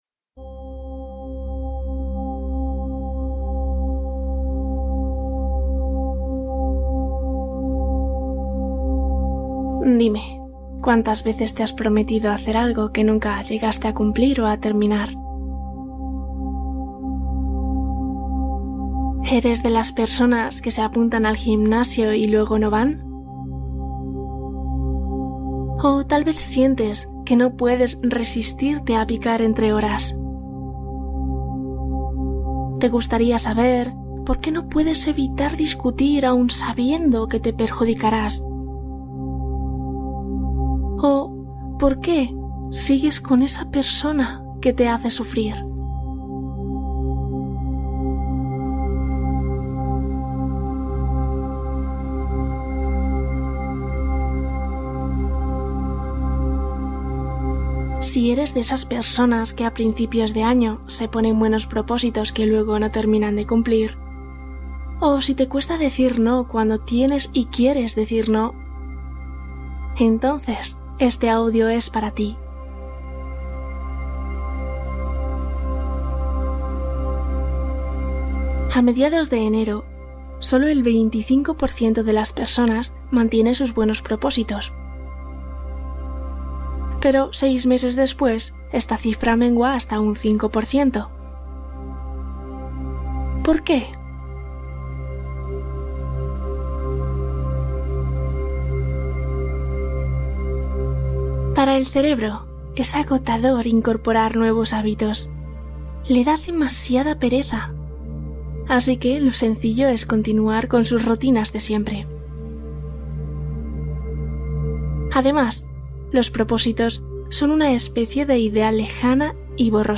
Hipnosis para fuerza de voluntad | Cumple metas con paz mental